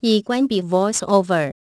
AXEFIAudio_zh_TW_VoiceOverOff.wav